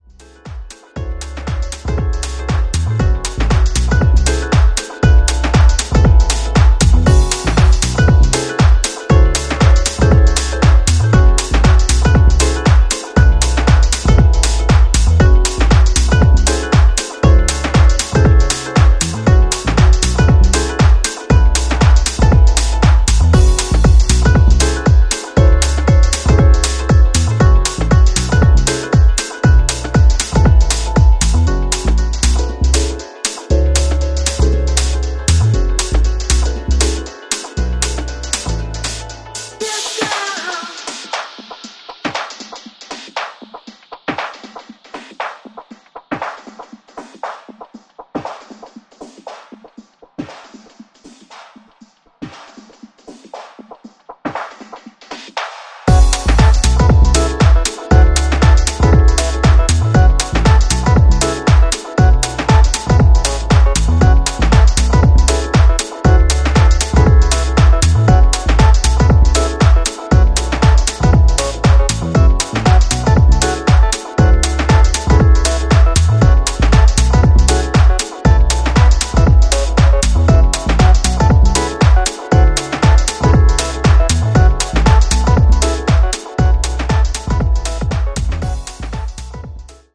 今回は、シカゴスタイルのディープハウスに仕上がったグッドな1枚です！
ジャンル(スタイル) DEEP HOUSE / HOUSE